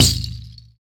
SI2 RATTL04R.wav